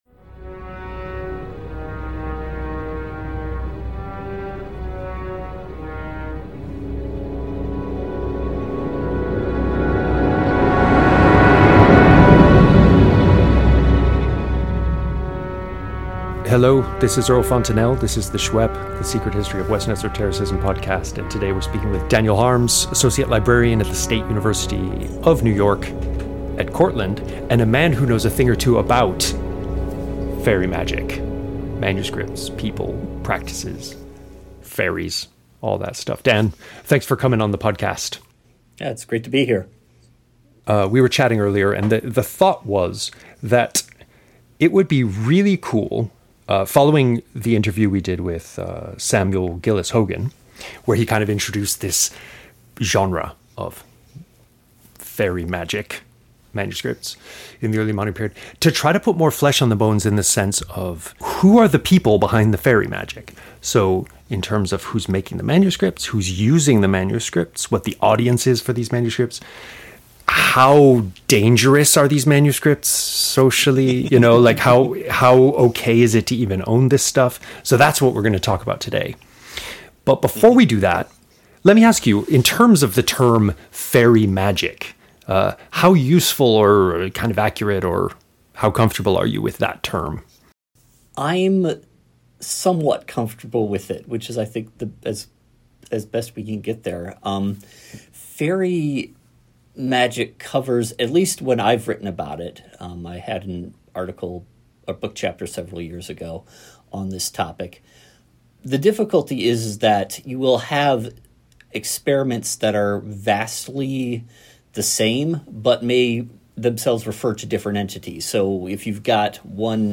We discuss ‘fairy-magic’ as a genre, its rise and fall in popularity, and more. Interview Bio